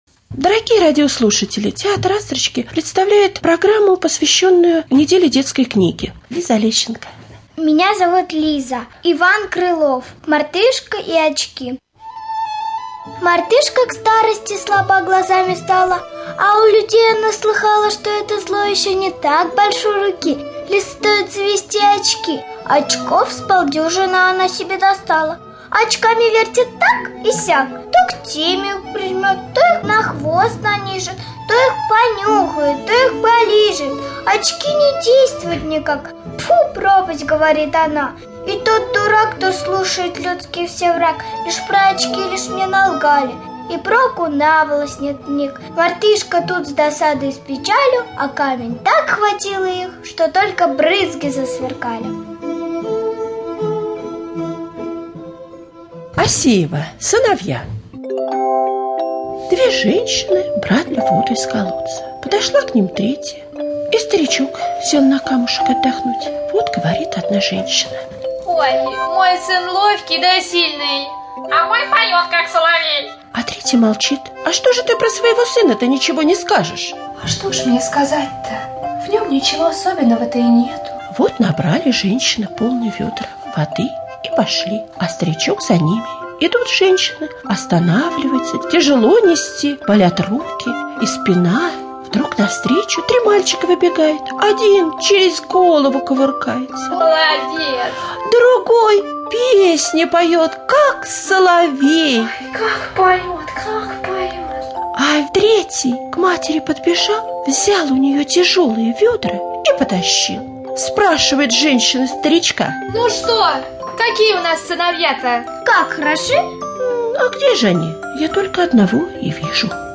2.2 апреля – День детской книги, в студии детский театр «Астрочки»